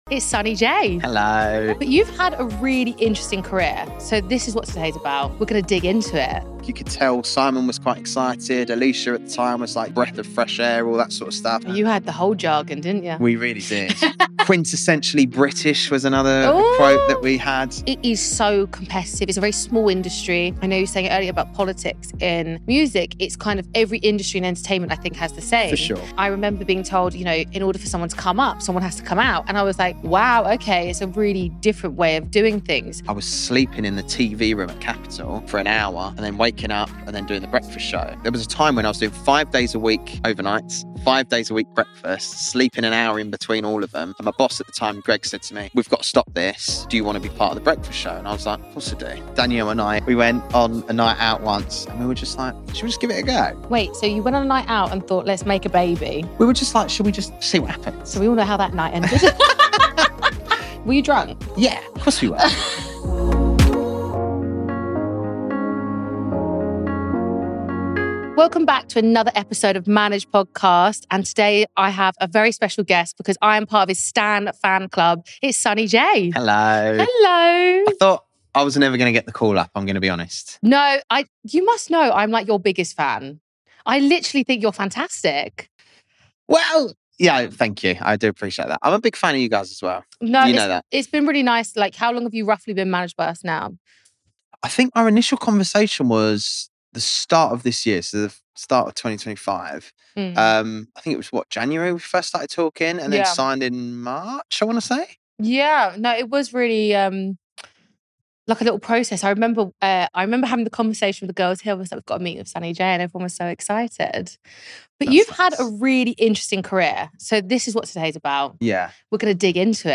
He also reflects on the realities of digital fame, creative choices, and why family now shapes every career decision. A candid conversation about ambition, reinvention, and what success looks like after the spotlight.